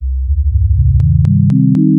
VST и Standalone microKORG кликают при исчерпании голосов (поставил ограничение в 4 голоса, как у железного).
Вложения microKORG_click.wav microKORG_click.wav 517,5 KB · Просмотры: 94